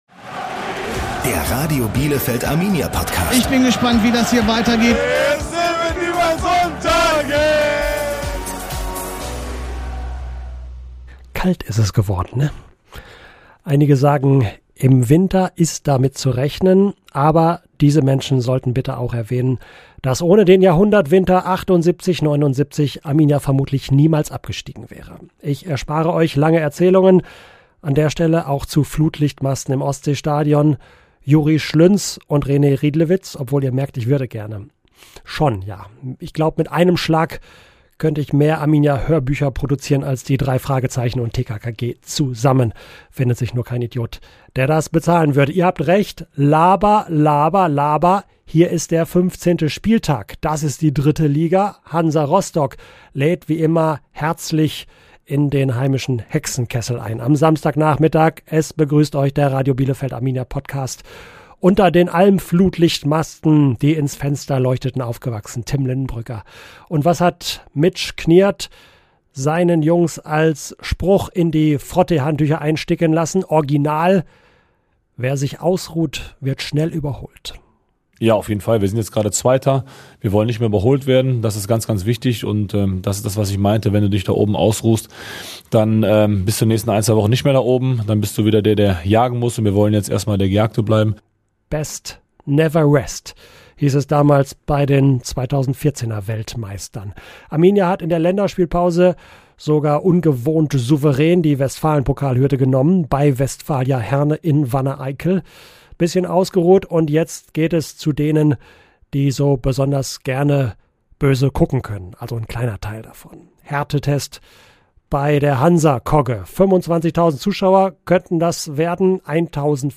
Im Radio Bielefeld Arminia-Podcast hört ihr Vorberichte vom Trainingsplatz der Blauen, die Highlights der Spiele in der Liga und die denkwürdigen Momente im DFB-Pokal. In diesem Fußball-Podcast erlebt ihr die Höhepunkte von der Alm noch einmal.